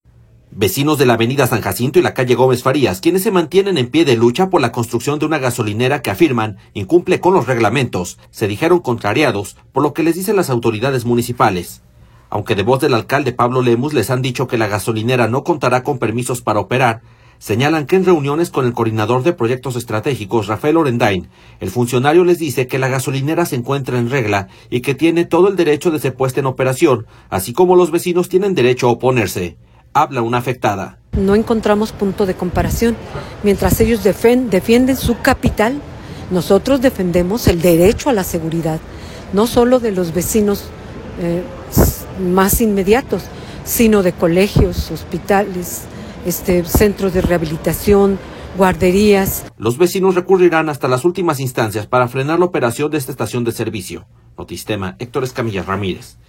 Habla una afectada: